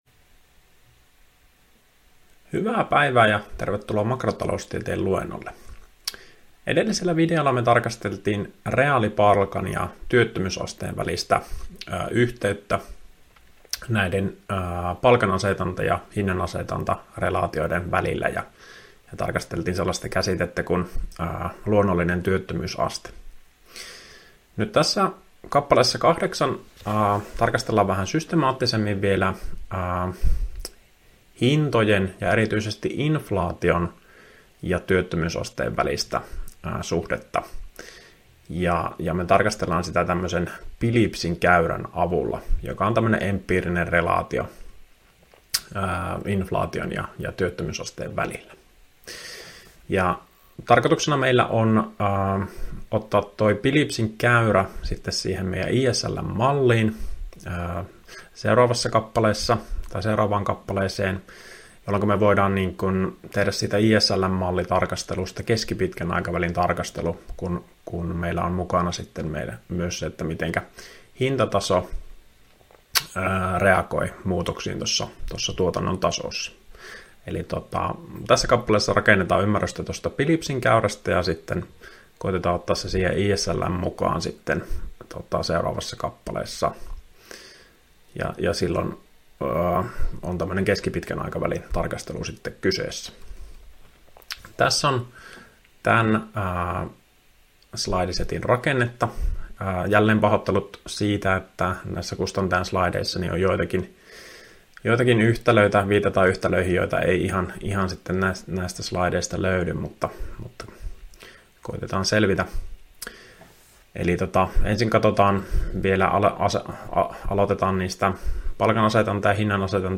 Opintojakson "Makrotaloustiede I" suhdanneosion 7. opetusvideo.